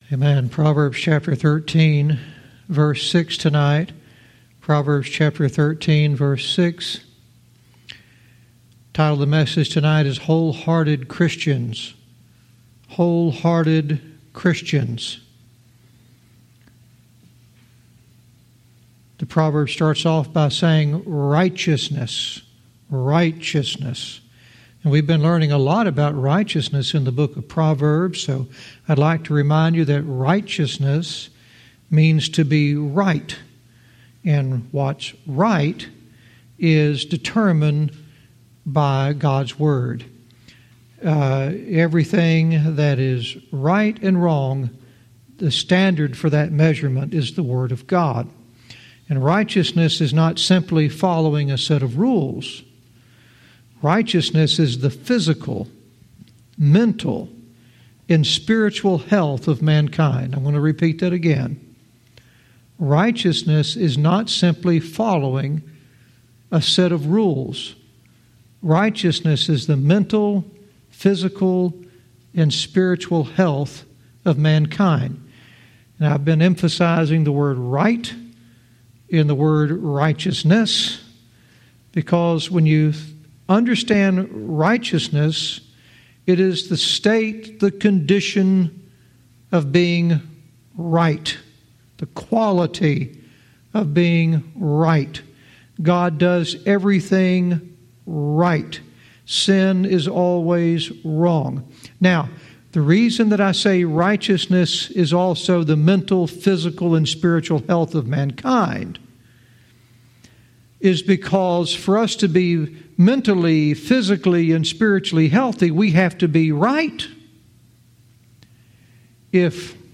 Verse by verse teaching - Proverbs 13:6 "Wholehearted Christians"